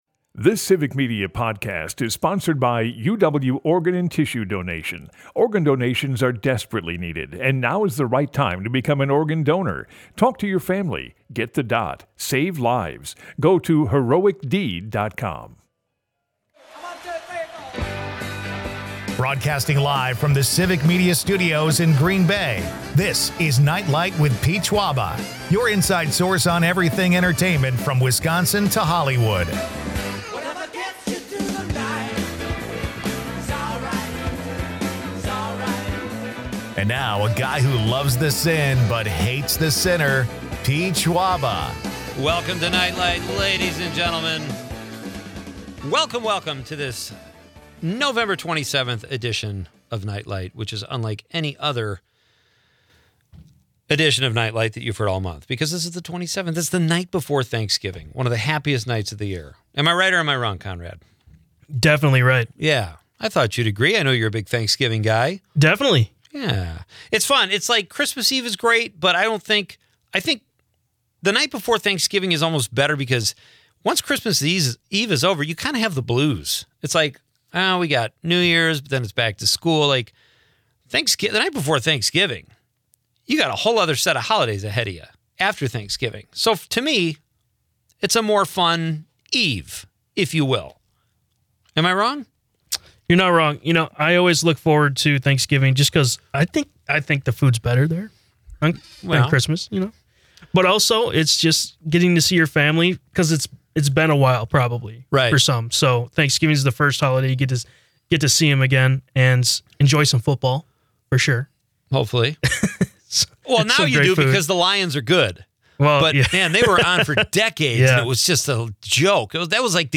Then we'll crush it with music